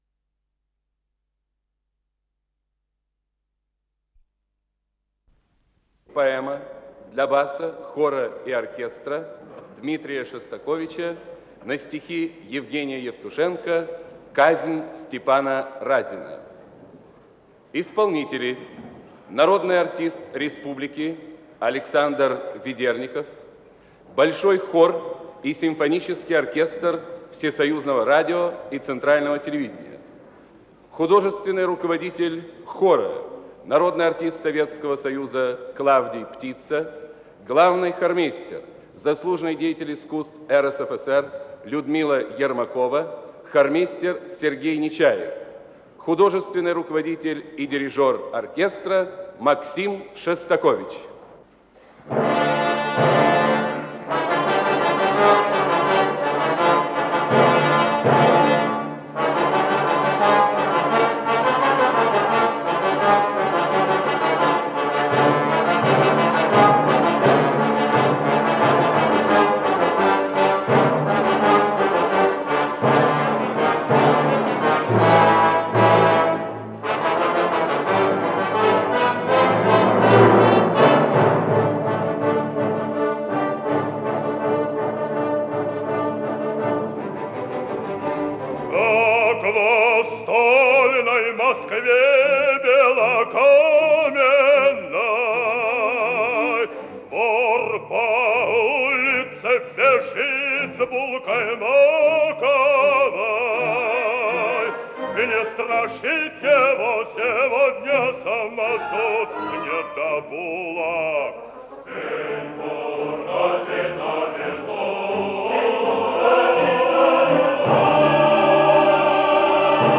Исполнитель: Александр Ведерников - пение Большой хор Всесоюзного радио и Центрального телевидения
Запись из Колонного зала Дома союзов от 17 ноября 1974 года